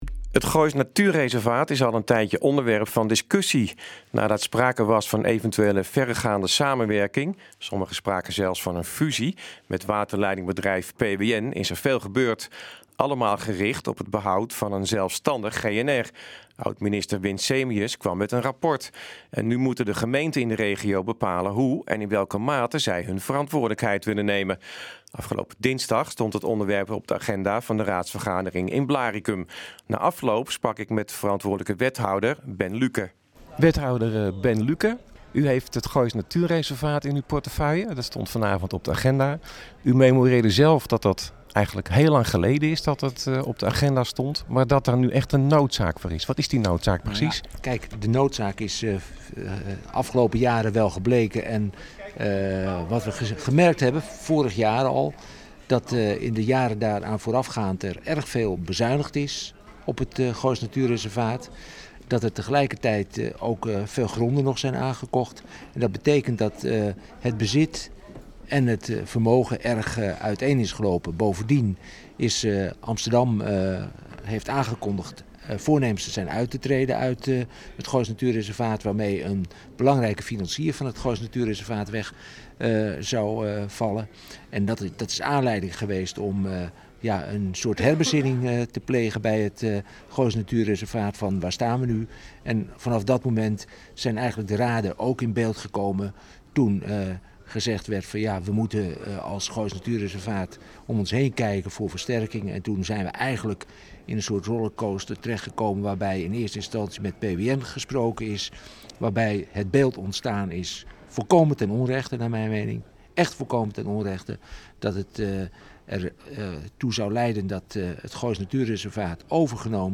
Op de gemeenten in de regio wordt een beroep gedaan hun verantwoordelijkheid te nemen waar het gaat om het zelfstandig voortbestaan van het GNR. Wethouder Ben Lüken vertelt wat Blaricum bijdraagt.